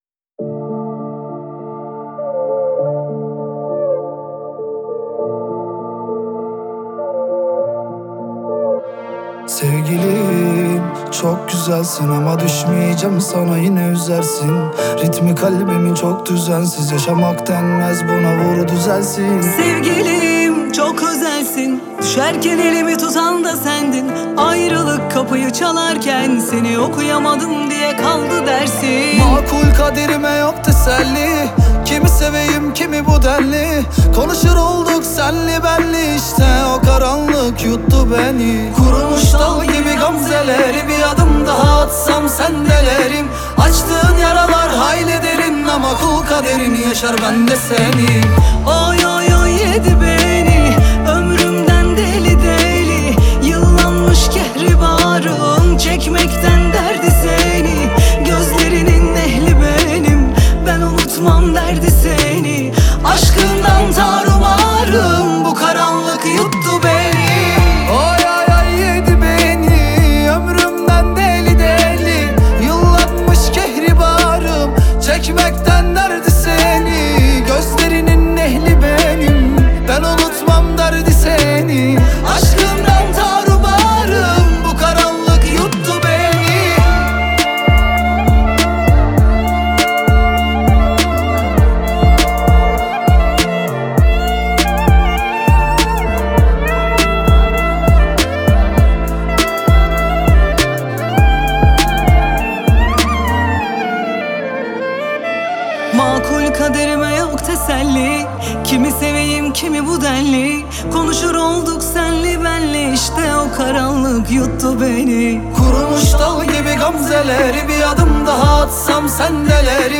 آهنگ ترکیه ای آهنگ غمگین ترکیه ای آهنگ هیت ترکیه ای ریمیکس